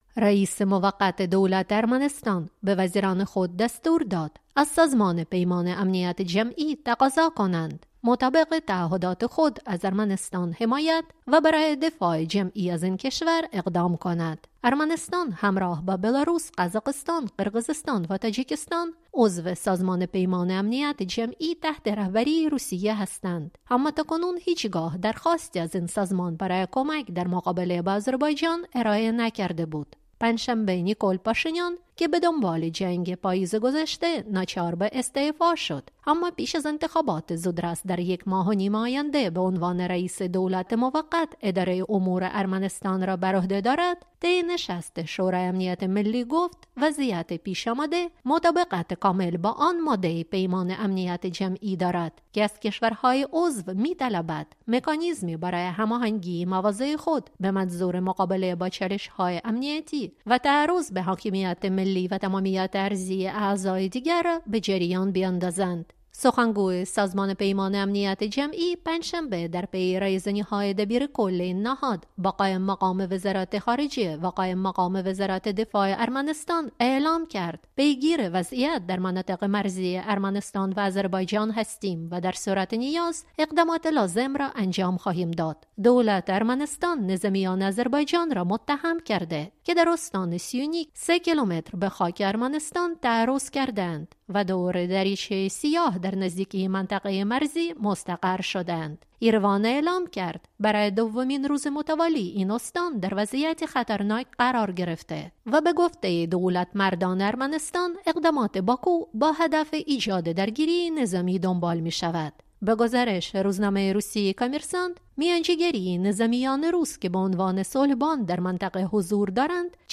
چنین درخواستی در بحبوحهٔ جنگ پاییز گذشته مطرح نشده بود. گزارشی